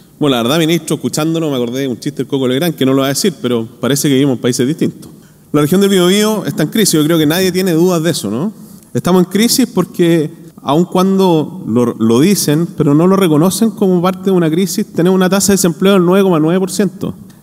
Lo anterior ocurrió en medio del Encuentro Regional de la Empresa (Erede), desarrollado en Concepción, región del Bío Bío, el cual contó con la presencia del Ministro de Economía y Energía, Álvaro García, y el titular de hacienda, Nicolás Grau.